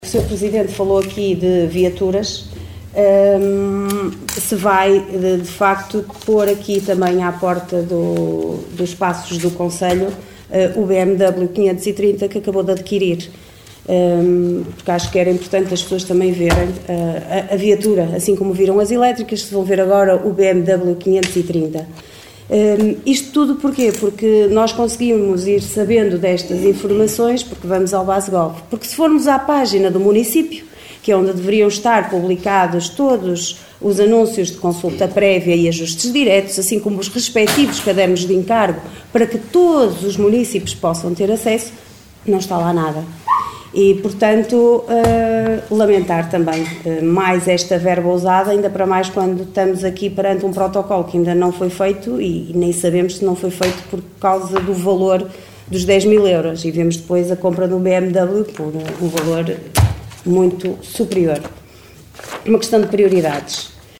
Excertos da reunião de Câmara realizada ontem à tarde nos Paços do Concelho.